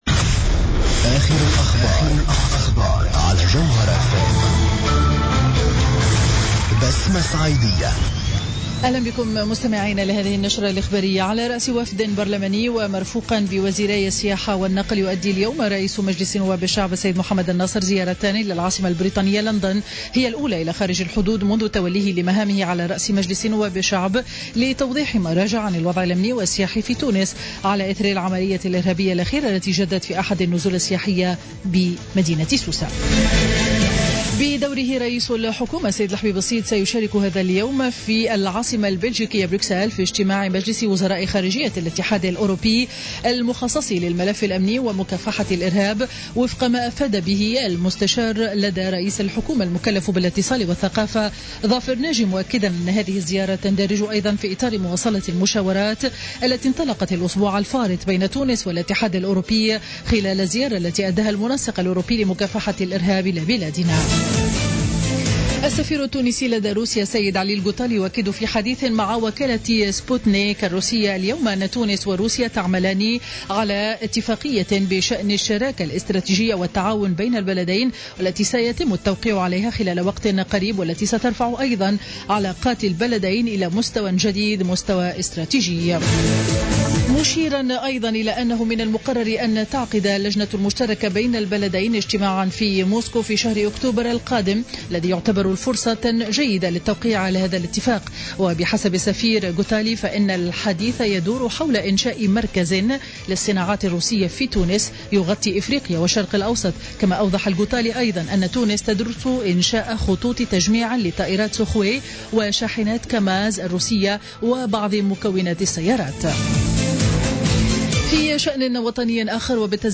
نشرة أخبار منتصف النهار ليوم الإثنين 20 جويلية 2015